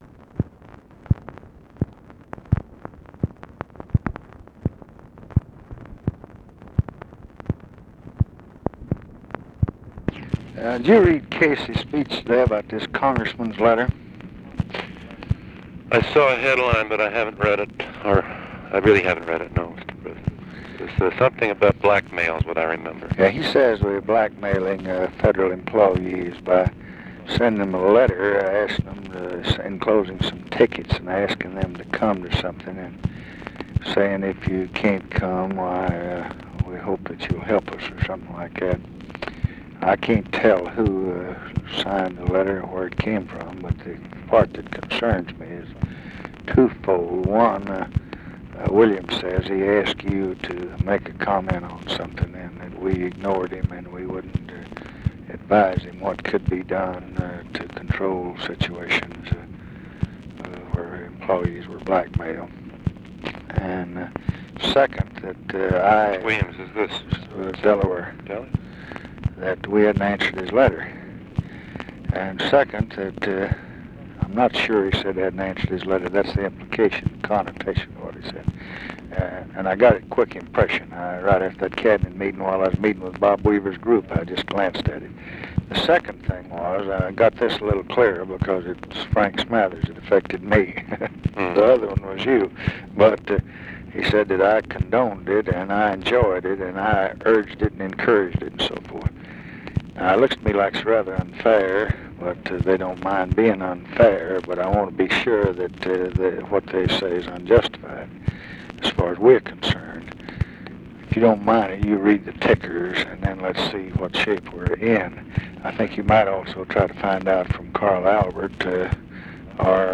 Conversation with NICHOLAS KATZENBACH, August 11, 1966
Secret White House Tapes